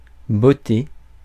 Ääntäminen
Ääntäminen US : IPA : /ˈkɪk/